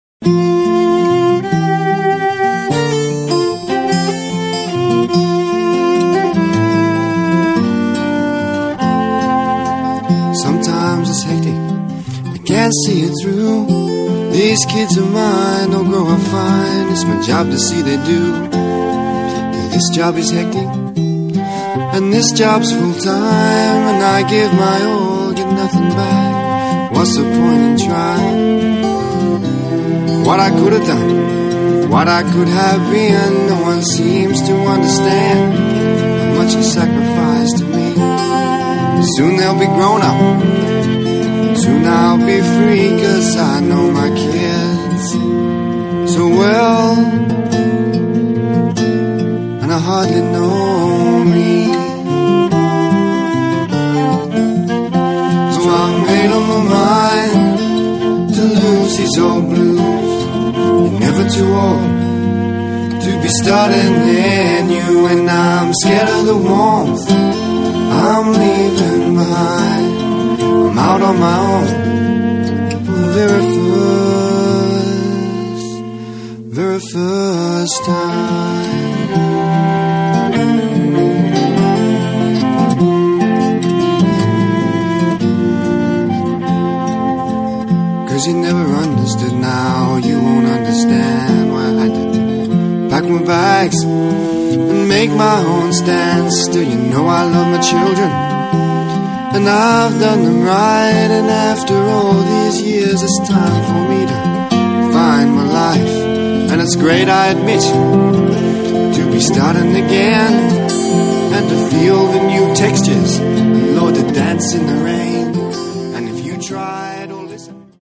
speelt op de eerste als gastmuzikant viool.
Je zou het folkblues kunnen noemen